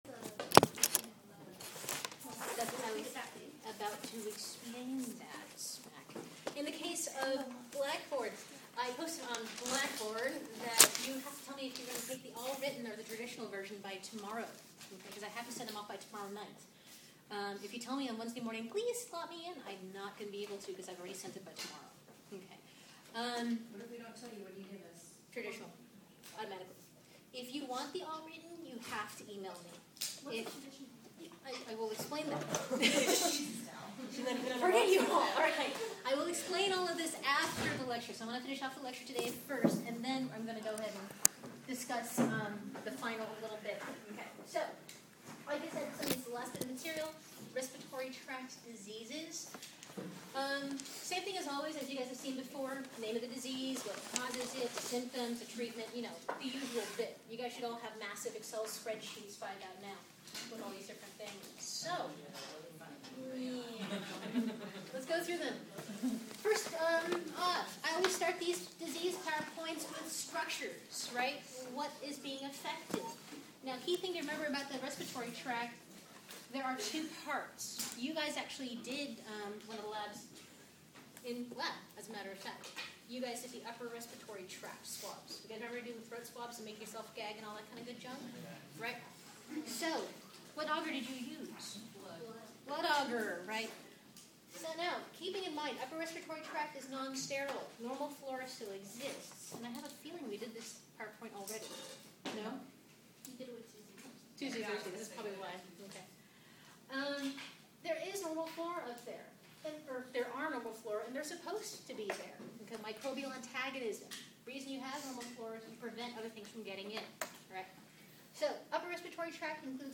Microbiology June 6, 2011 lecture